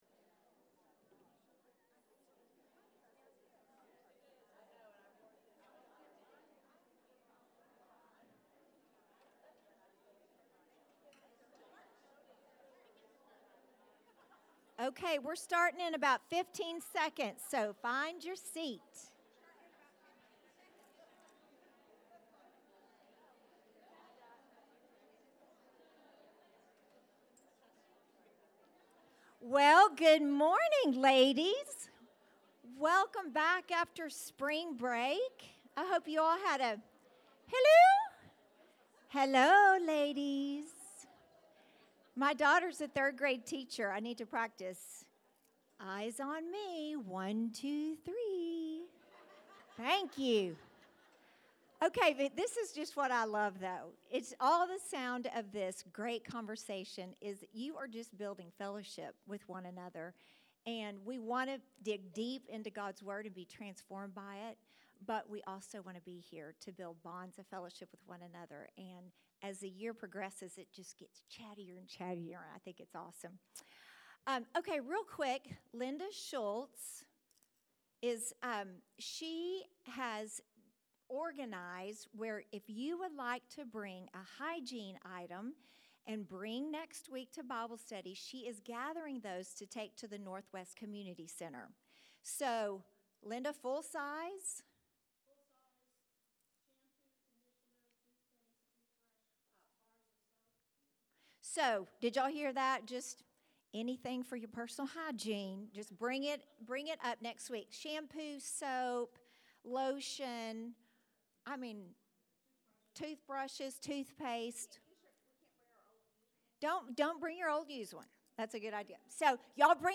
Women’s Bible Study: Week 11